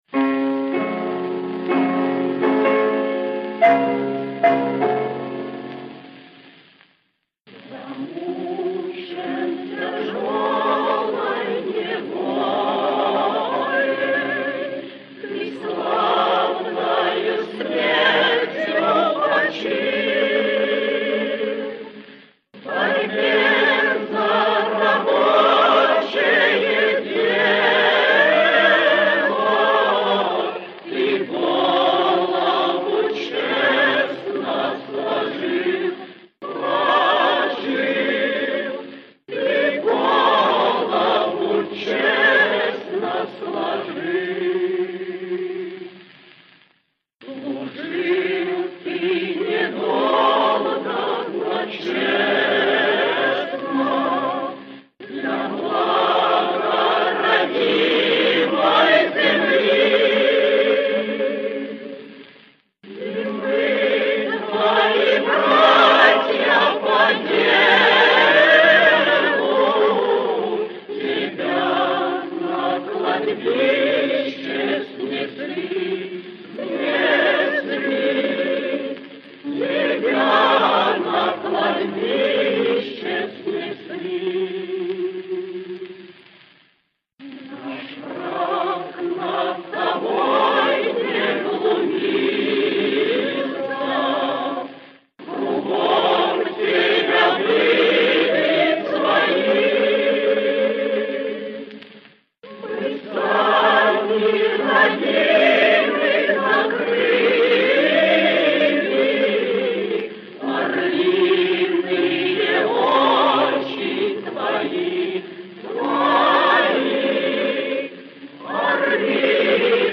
Известнейшая революционная песня.